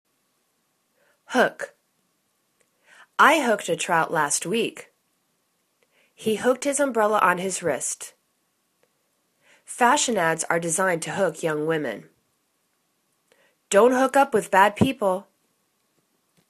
hook      /hok/    v